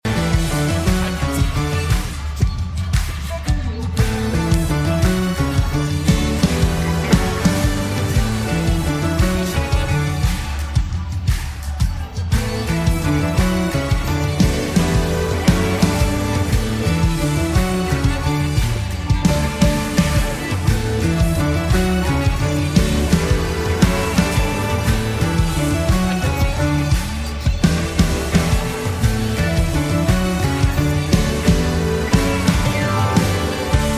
PianoArrangementWorshipKeys